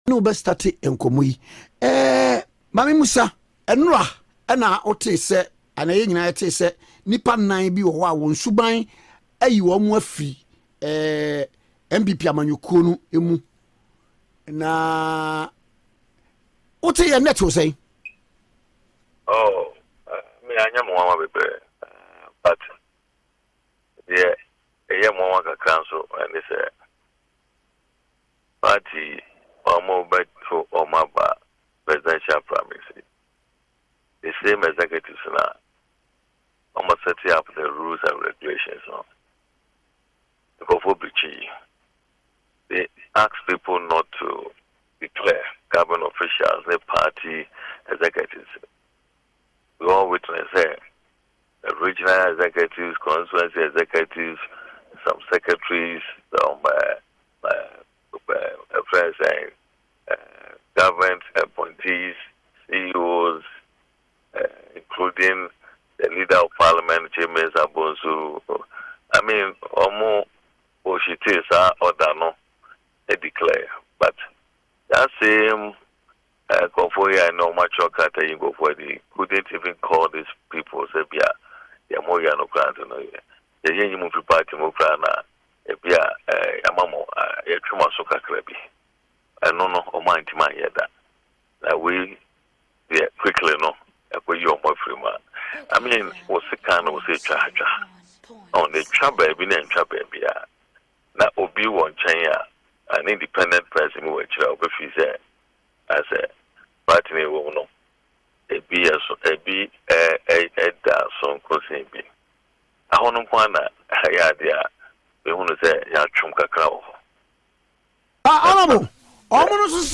In an interview on Okay FM on November 21, 2023, he pointed to a perceived discrepancy in the treatment of individuals who have allegedly violated party rules, referencing President Nana Addo Dankwa Akufo-Addo’s involvement in a similar act that did not result in similar consequences.